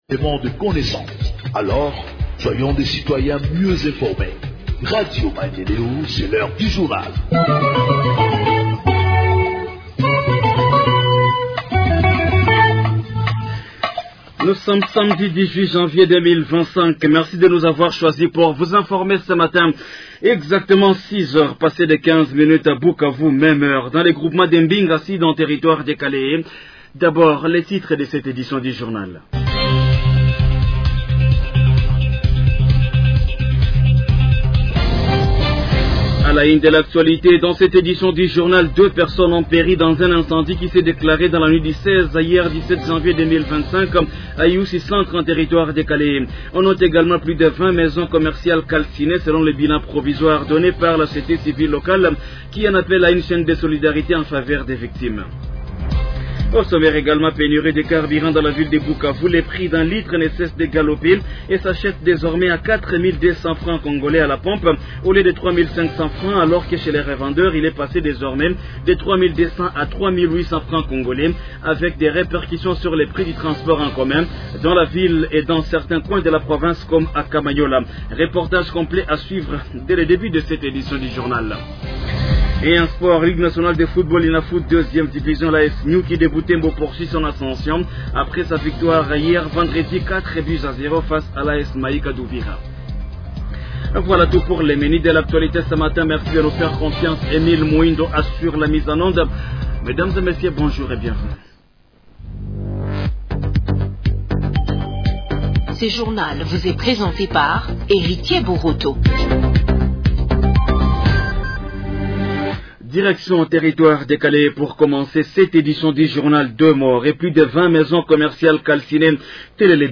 Journal en Français du 18 janvier 2025 – Radio Maendeleo